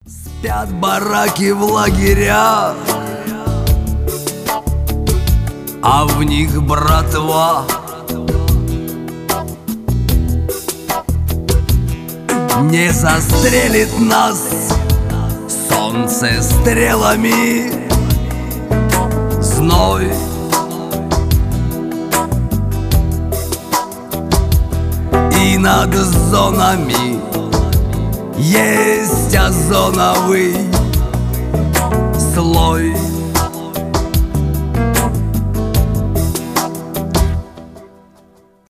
блатные
шансон